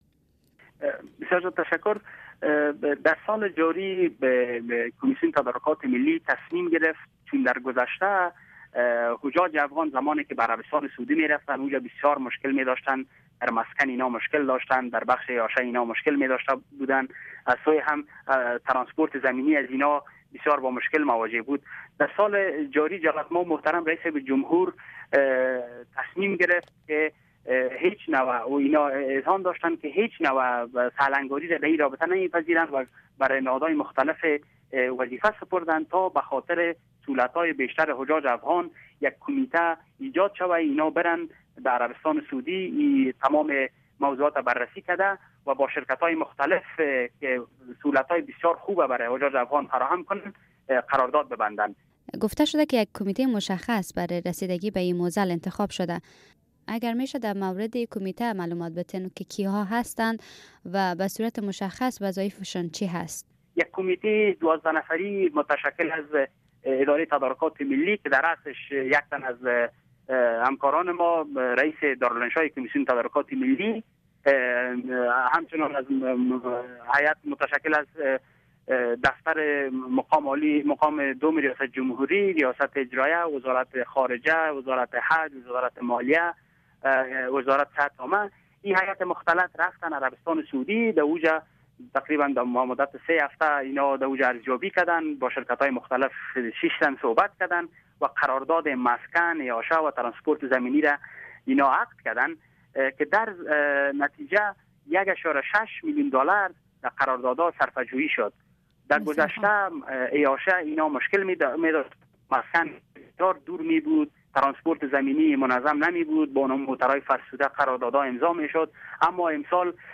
مصاحبۀ کامل